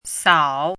chinese-voice - 汉字语音库
sao3.mp3